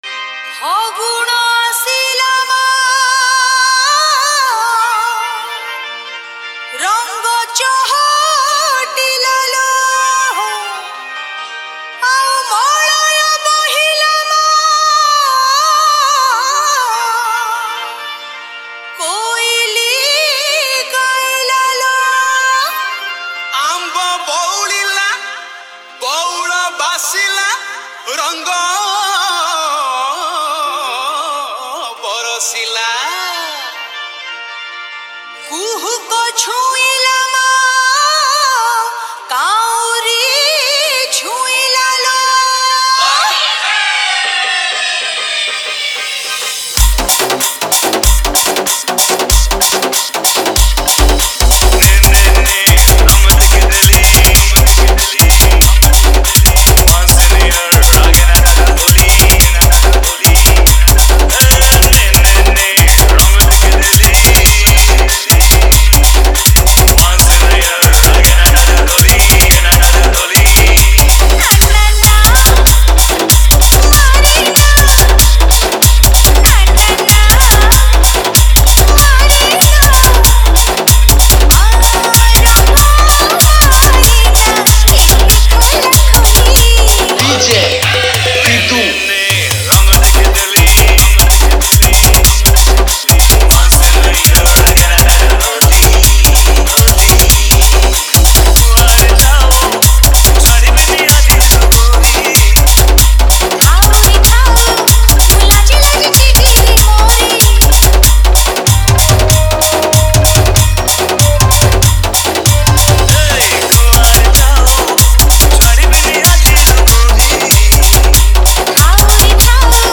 HOLI SPECIAL DJ SONG